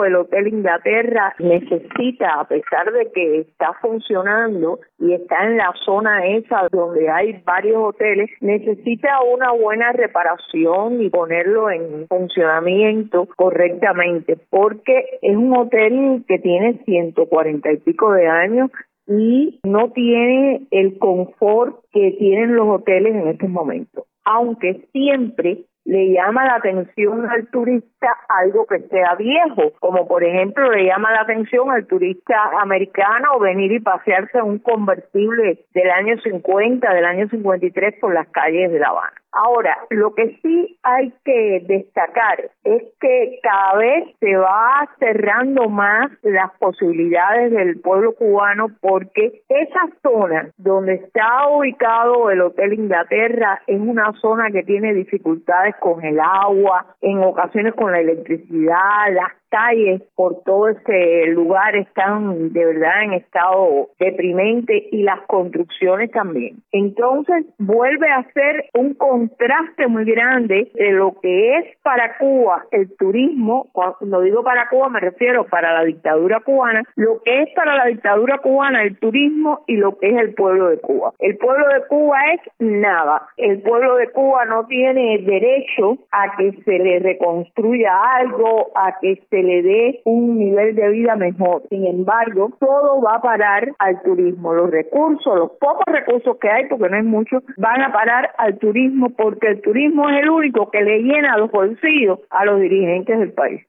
Hotel Inglaterra. Entrevista